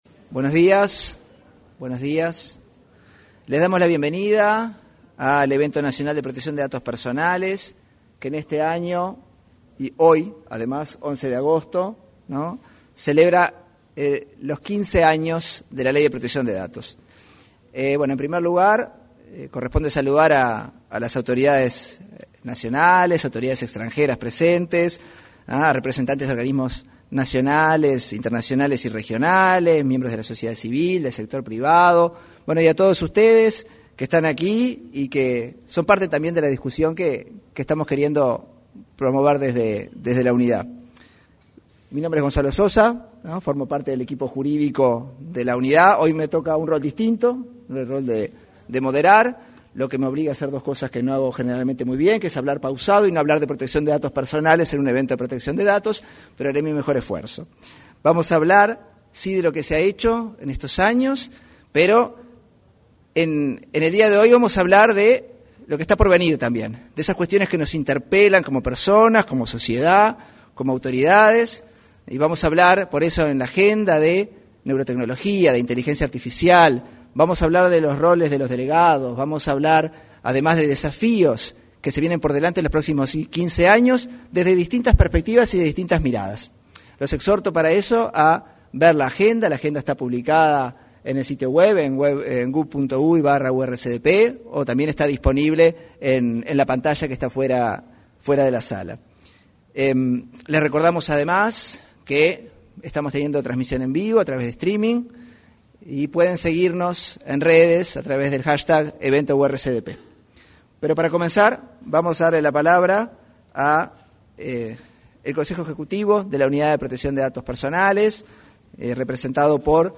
Este viernes 11, en el auditorio del anexo de la Torre Ejecutiva, se efectuó la apertura de un evento sobre protección de datos personales.
En la oportunidad, se expresaron el presidente del Consejo Ejecutivo de la Unidad Reguladora y de Control de Datos Personales, Felipe Rotondo, y el director ejecutivo de la Agencia de Gobierno Electrónico y Sociedad de la Información y del Conocimiento (Agesic), Hebert Paguas.